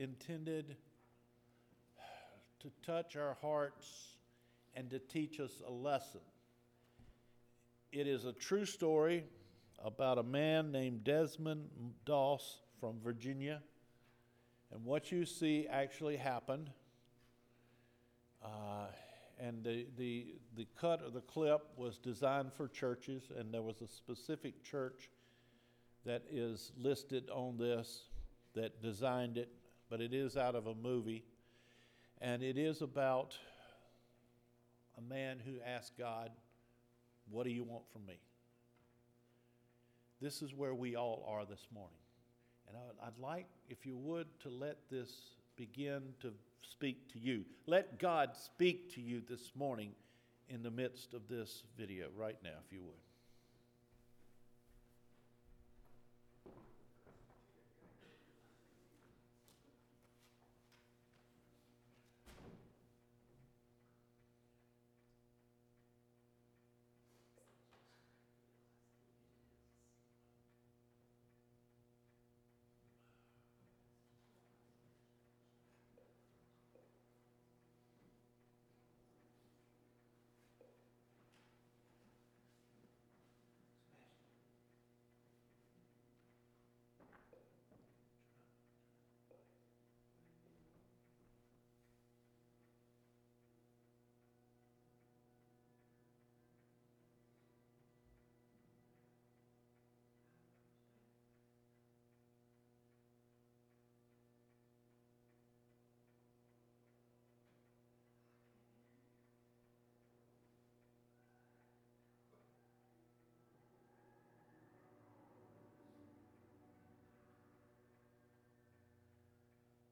LORD JUST ONE MORE – FEBRUARY 9 SERMON